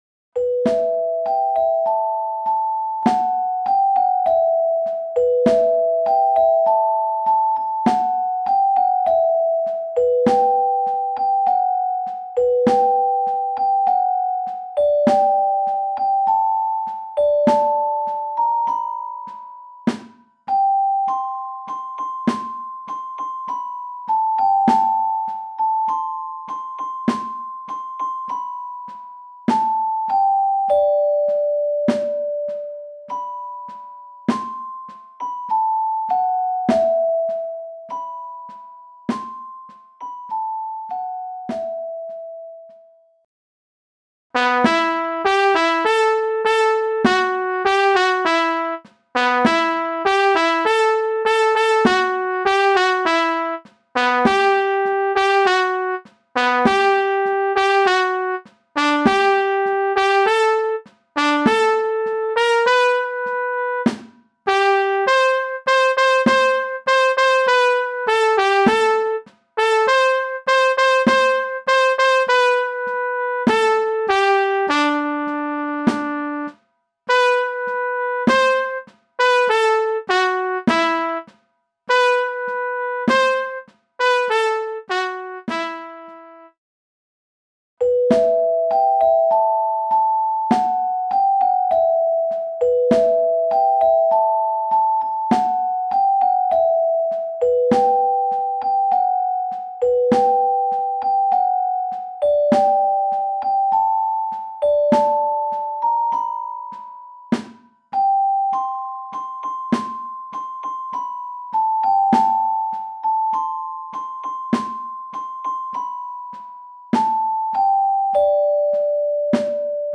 MIDI von 2001 [8.623 KB] - mp3